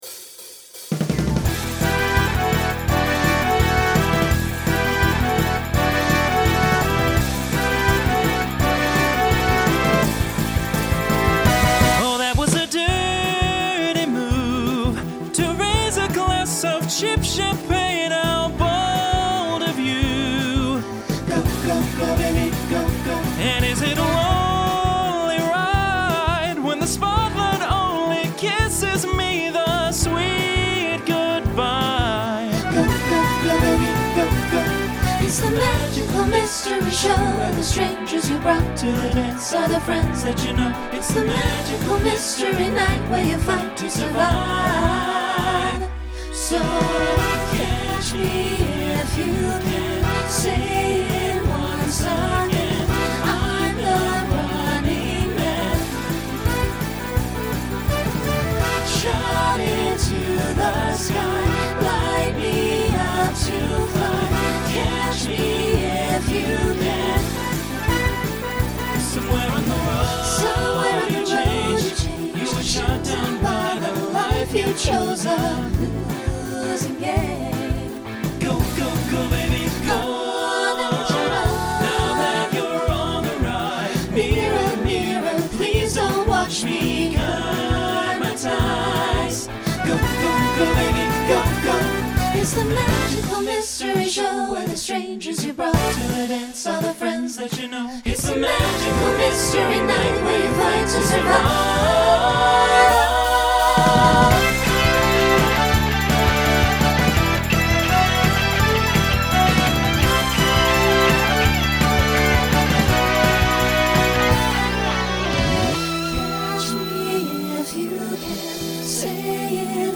Genre Rock Instrumental combo
Opener Voicing SATB